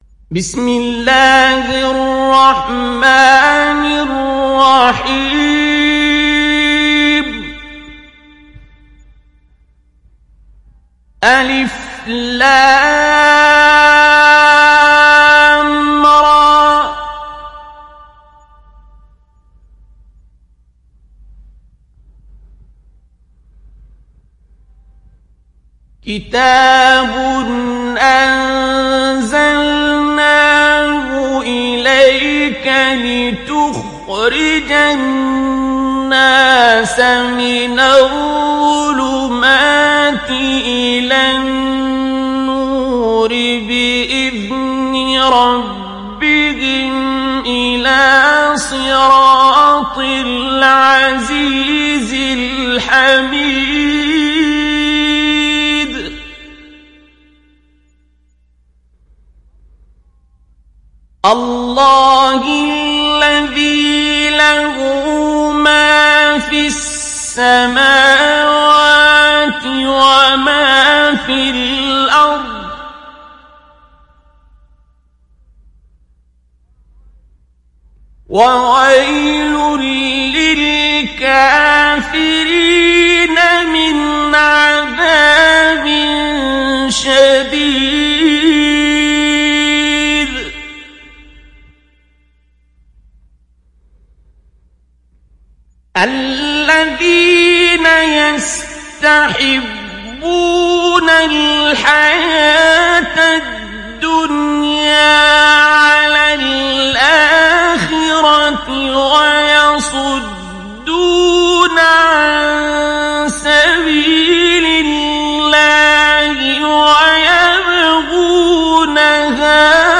İndir Ibrahim Suresi Abdul Basit Abd Alsamad Mujawwad